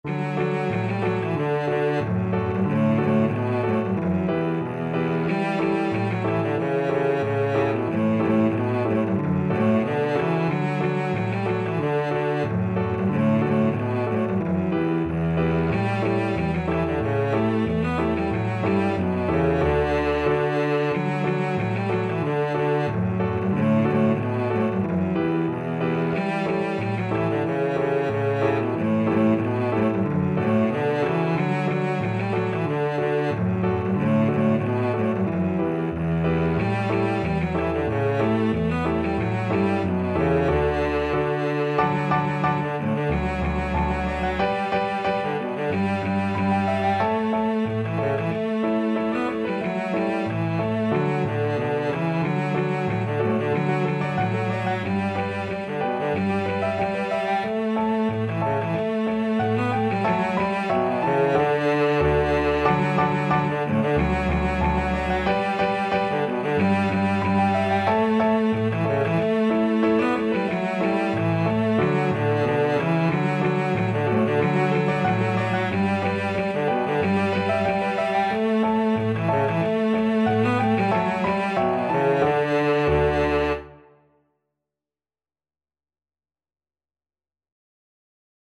Cello version
Traditional Music of unknown author.
2/4 (View more 2/4 Music)
Moderato =c.92
Danish